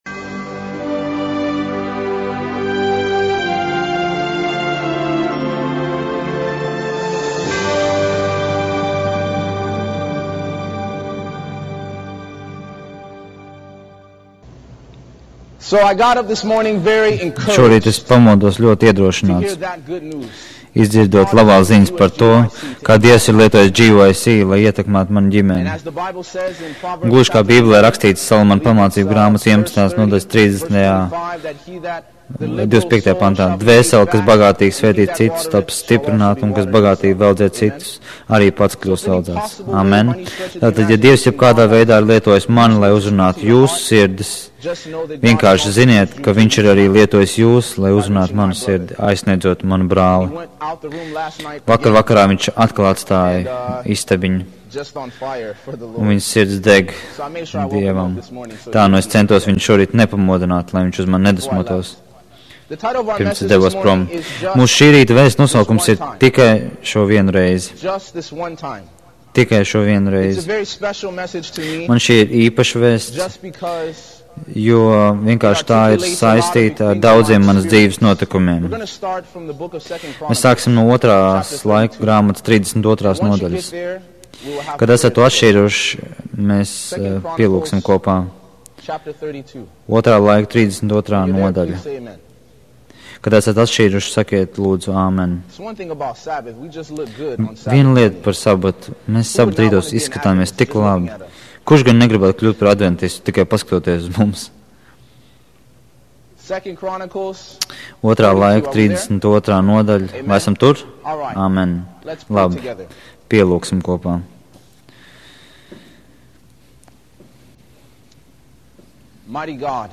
Viņš ir jauns sludinātājs ar degsmi vest ļaudis atpakaļ pie Dieva Vārda.